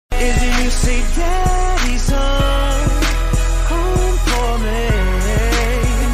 Daddys Home Meme Sound sound effects free download